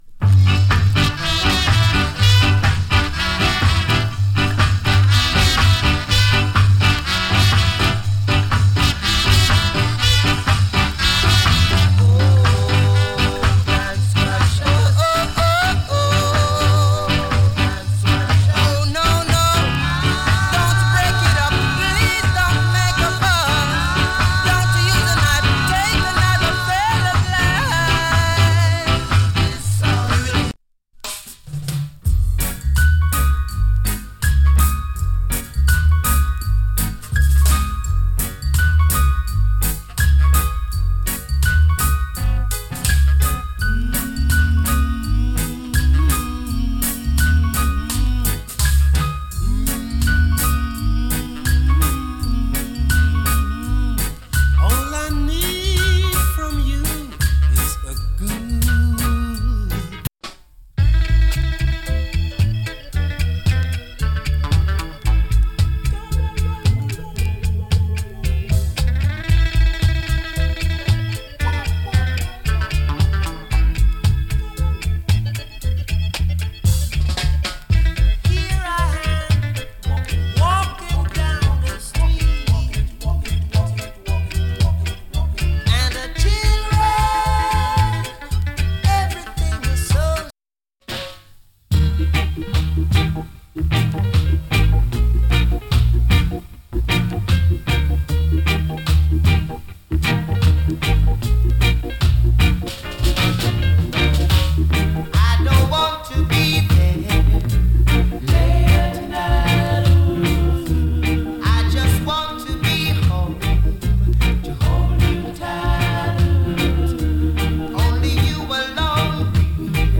周期的にパチノイズ少し有り。全体的にも少しパチノイズ有り。